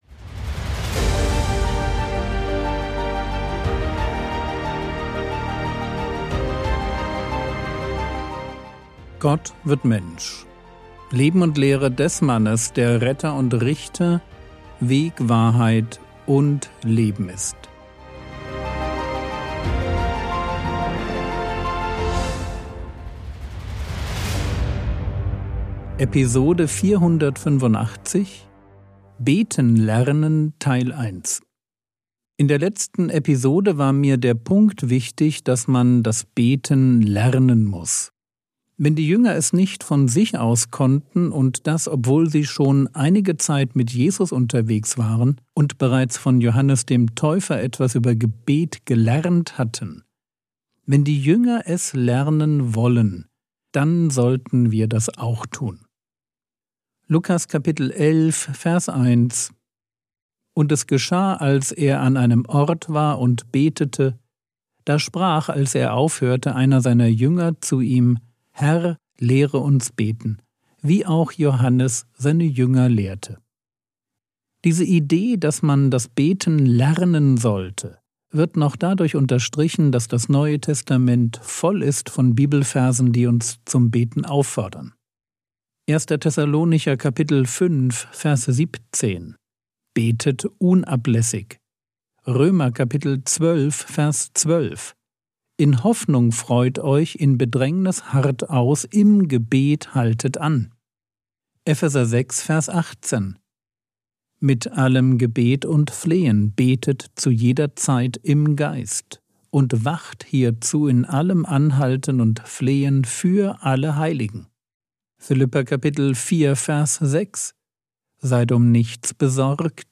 Episode 485 | Jesu Leben und Lehre ~ Frogwords Mini-Predigt Podcast